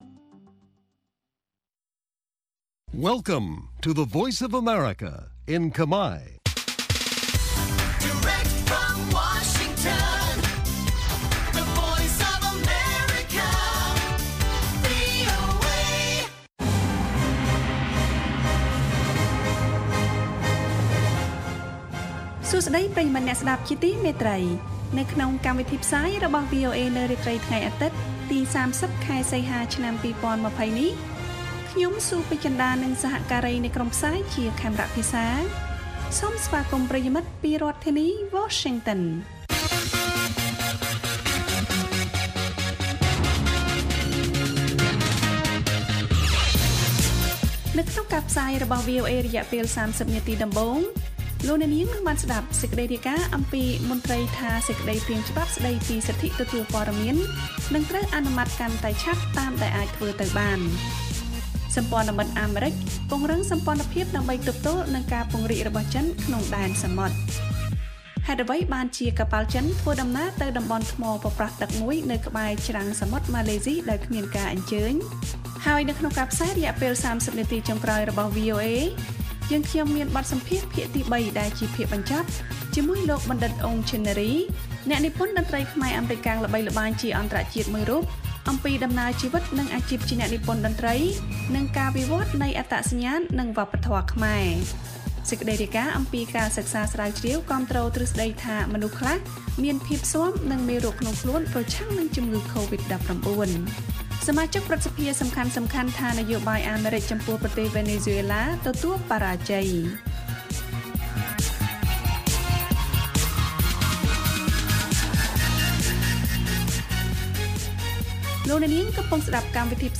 ព័ត៌មានសំខាន់ៗអំពីកម្ពុជាមានដូចជា មន្ត្រីកម្ពុជាថា សេចក្តីព្រាងច្បាប់ស្តីពីសិទ្ធិទទួលព័ត៌មាននឹងត្រូវអនុម័តកាន់តែឆាប់តាមតែអាចធ្វើទៅបាន។ នៅក្នុងបទសម្ភាសន៍ «កម្ពុជាច្នៃប្រឌិត» មានការពិភាក្សាជាមួយ អ្នកនិពន្ធតន្ត្រីល្បី បណ្ឌិត អ៊ុង ជិនណារី ជំរុញឲ្យសិល្បករស្តាប់អារម្មណ៍ក្នុងខ្លួន (ភាគ៣)។ រីឯព័ត៌មានអន្តរជាតិវិញមានដូចជា សម្ព័ន្ធមិត្តអាមេរិកពង្រឹងសម្ព័ន្ធភាពដើម្បីទប់ទល់នឹងការពង្រីកឥទ្ធិពលរបស់ចិនក្នុងដែនសមុទ្រ។